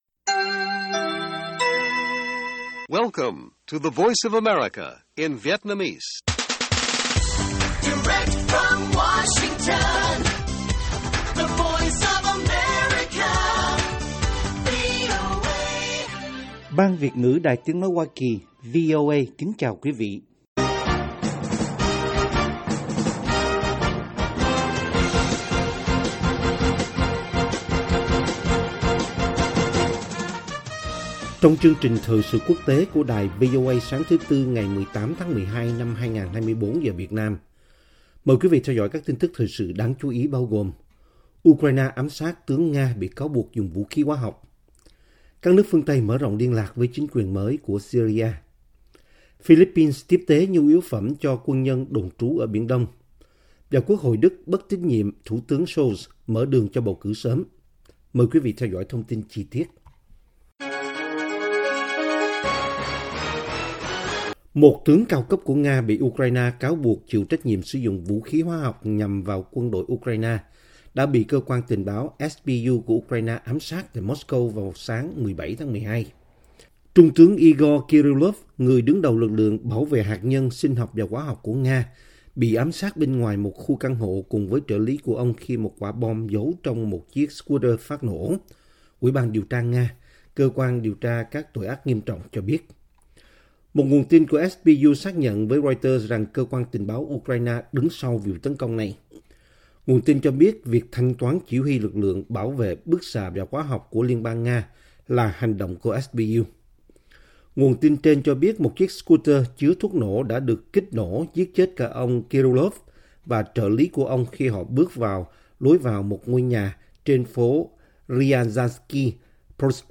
Tin tức mới nhất và các chuyên mục đặc biệt về Việt Nam và Thế giới. Các bài phỏng vấn, tường trình của các phóng viên ban Việt ngữ về các vấn đề liên quan đến Việt Nam và quốc tế.